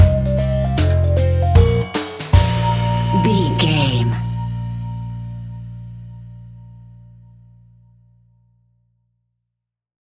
Ionian/Major
B♭
instrumentals
childlike
happy
kids piano